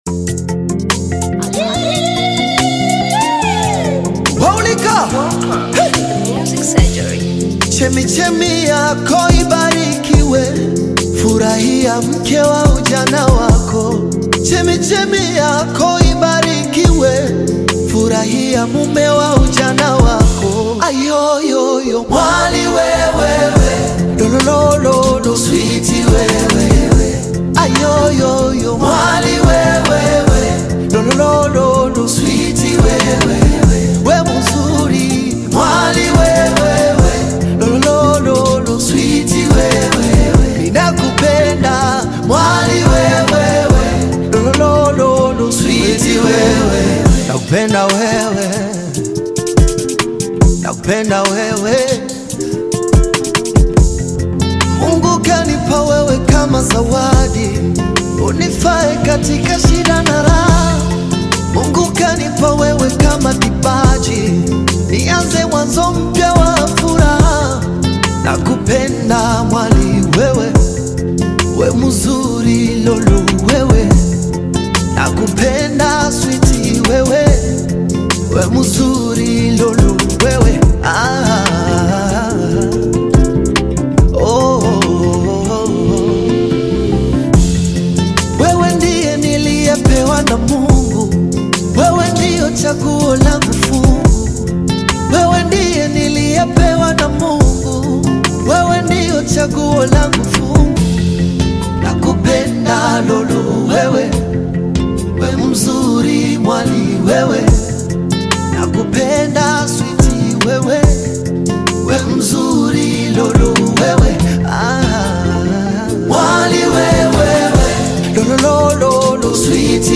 Gospel music track
Tanzanian Gospel artist, singer, and songwriter
Gospel song